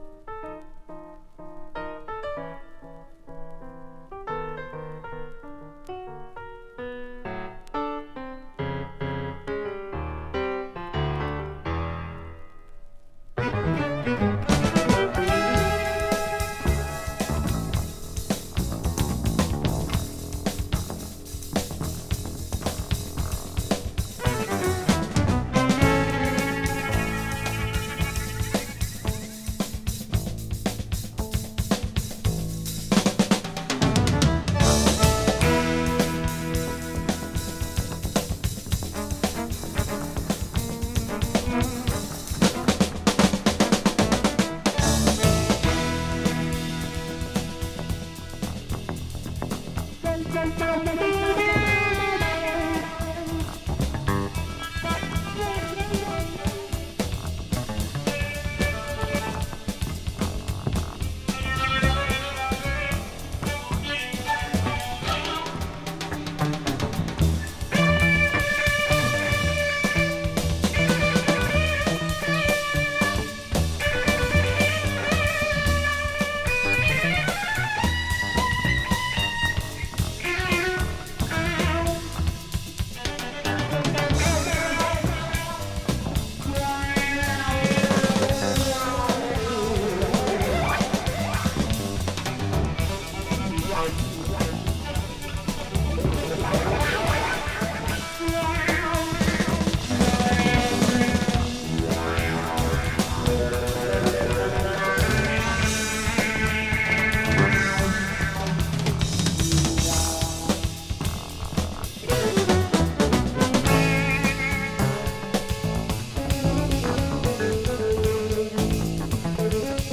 Psychedelic Jazz Funk
【ETHNIC】 【JAZZ FUNK】